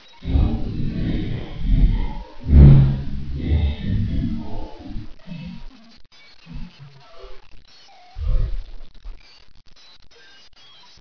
x_drowning.ogg